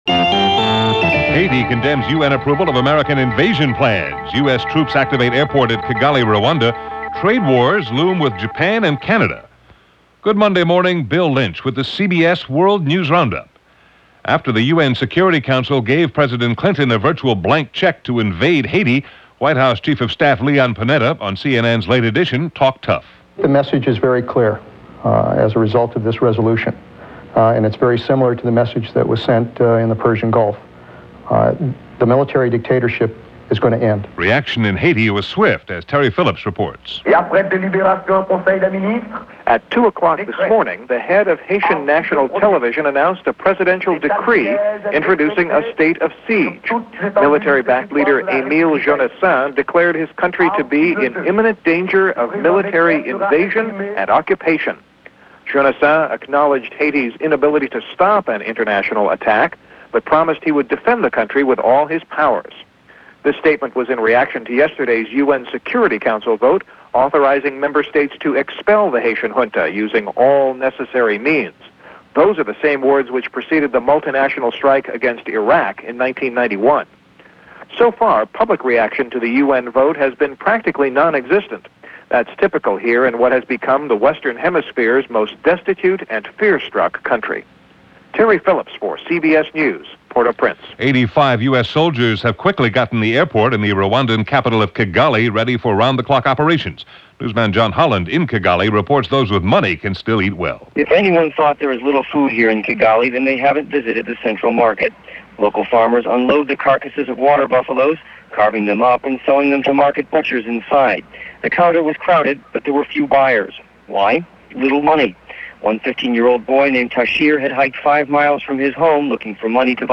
And as days go, so went the world on this crazy August 1st in 1994 as reported by The CBS World News Roundup.